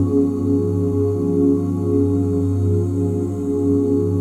OOHG FLAT5.wav